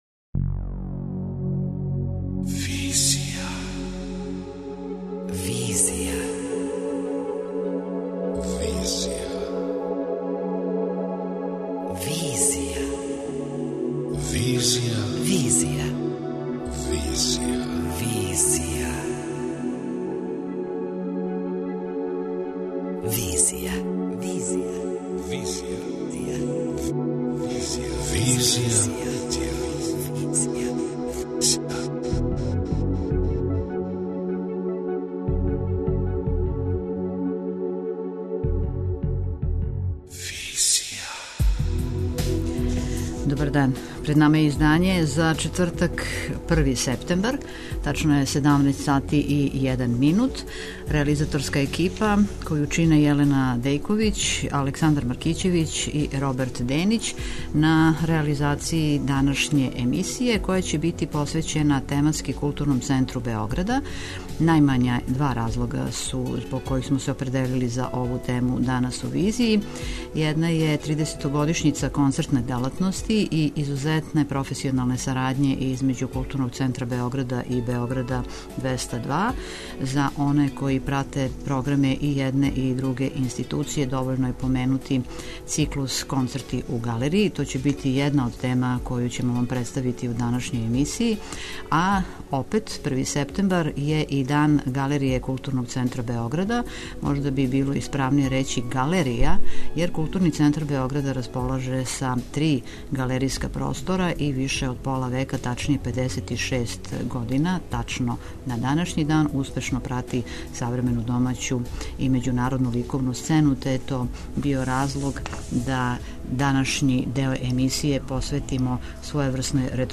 преузми : 25.98 MB Визија Autor: Београд 202 Социо-културолошки магазин, који прати савремене друштвене феномене.